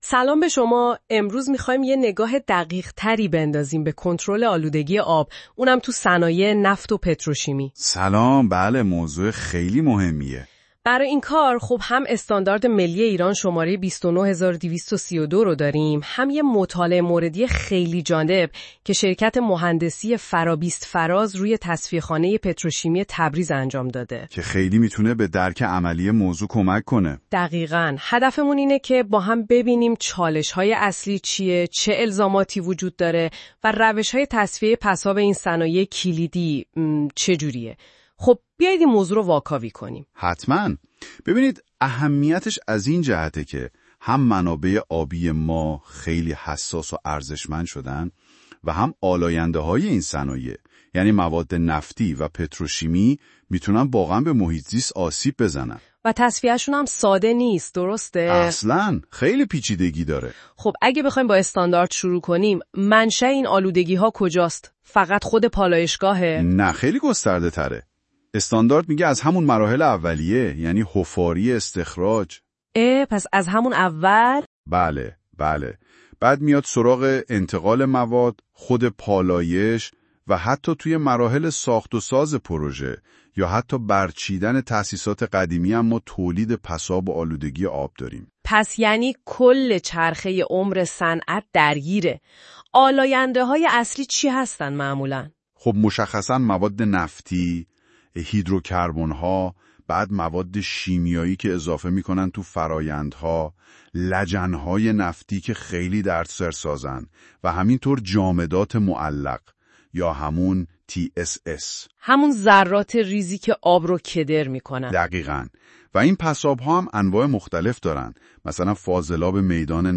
اگر فرصت خواندن این مقاله را ندارید به راحتی با پخش فایل صوتی زیر محتوای این مقاله را در قالب یک مکالمه جذاب بشنوید.